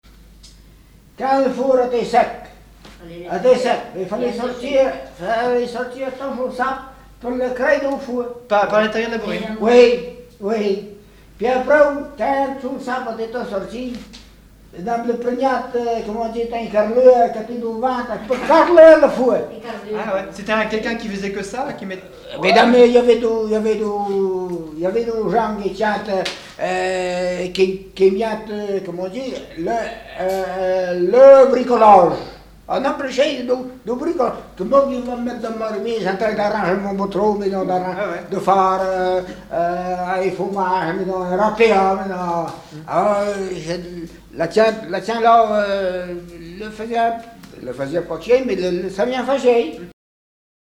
Témoignage sur la vie dans une bourrine
Catégorie Témoignage